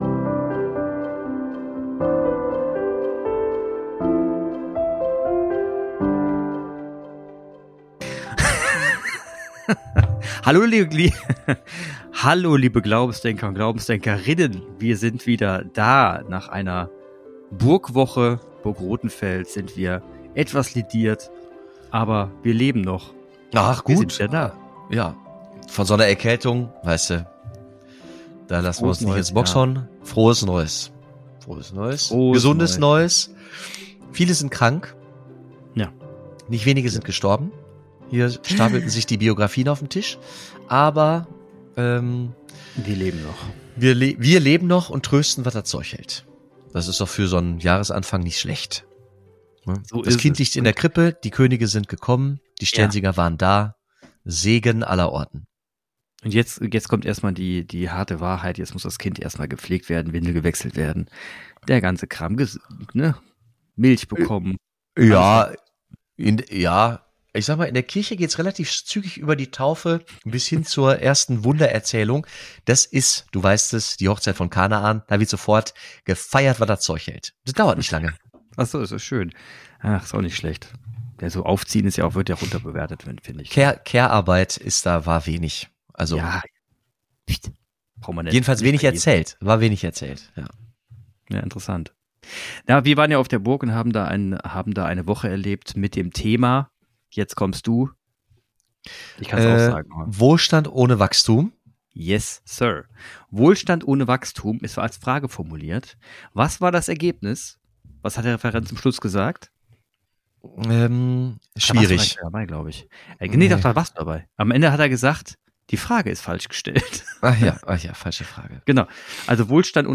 Agnostiker trifft Priester! -- Über Glauben wird selten gesprochen.